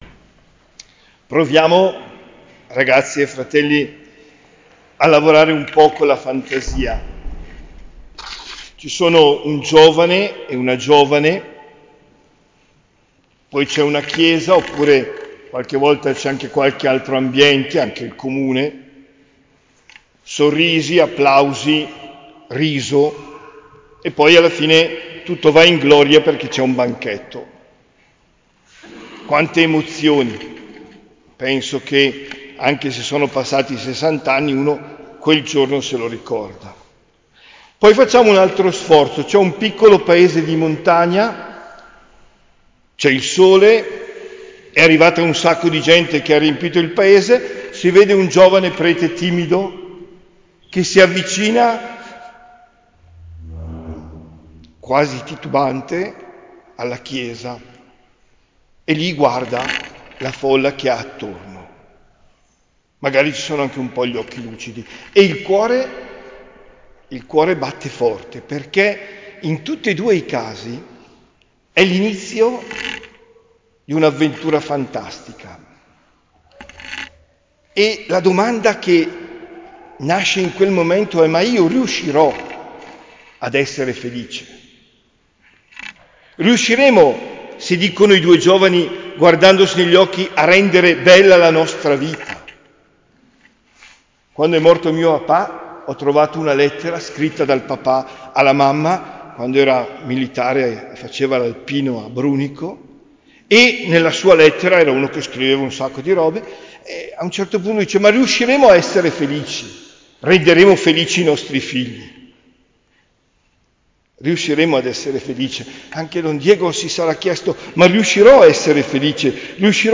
OMELIA DEL 13 OTTOBRE 2024